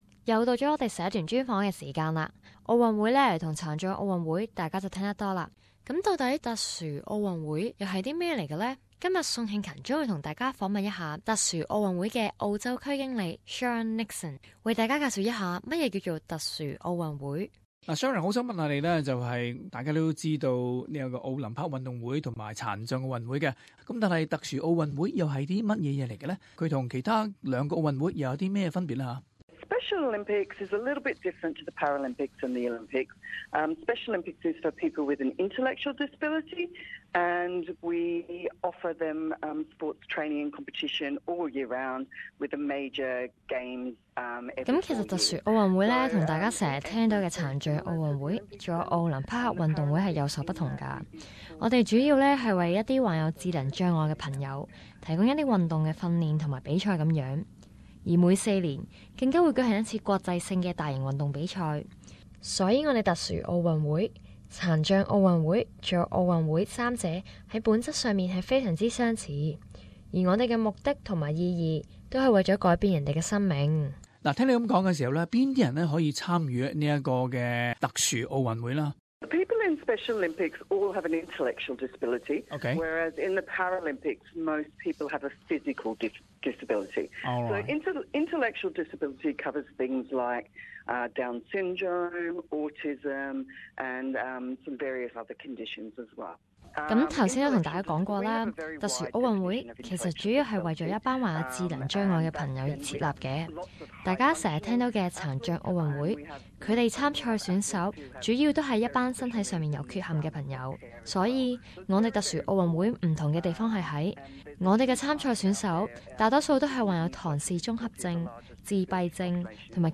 Community Interview Source: Getty Images